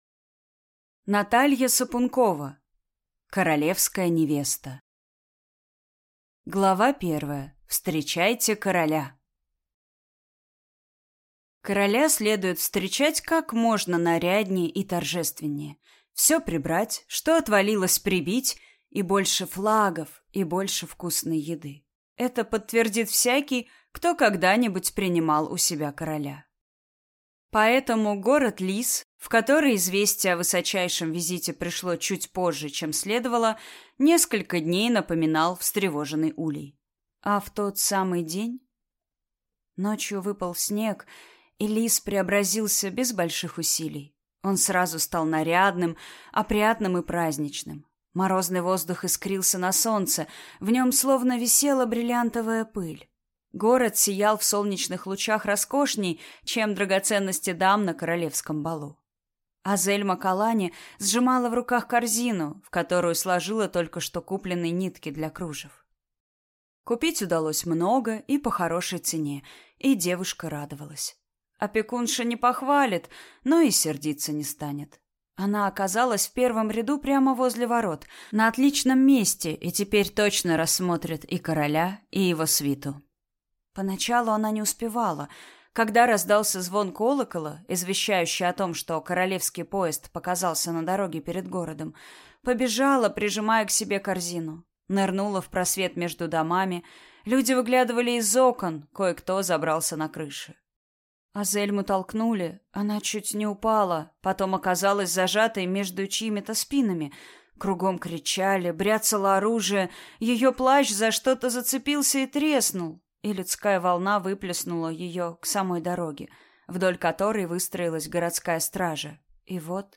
Аудиокнига Королевская невеста | Библиотека аудиокниг